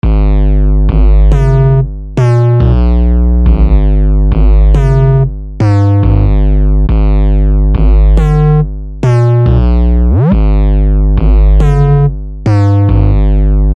描述：Heavy bass hits.
标签： 140 bpm Dubstep Loops Bass Loops 2.31 MB wav Key : Unknown